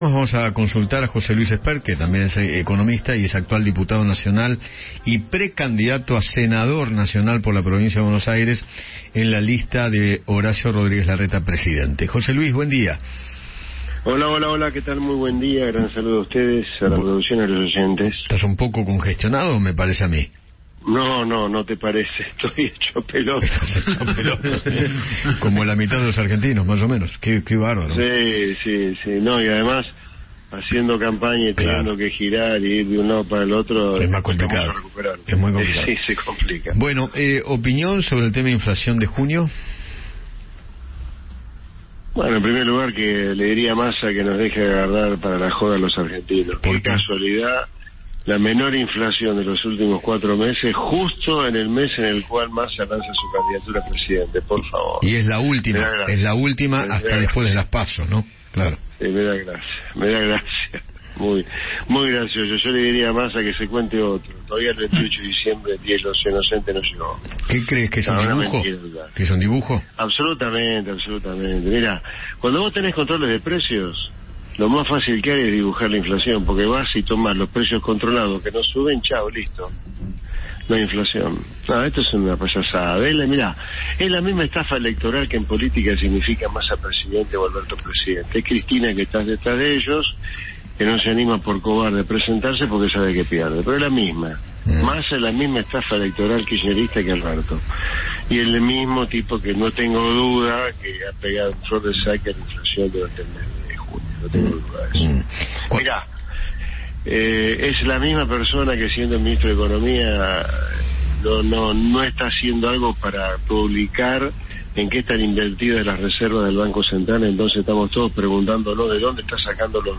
José Luis Espert, precandidato a senador por la provincia de Buenos Aires, dialogó con Eduardo Feinmann sobre el índice de inflación de Junio y sostuvo que “es un dibujo absolutamente”.